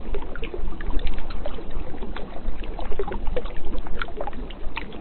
default_flow_lava.ogg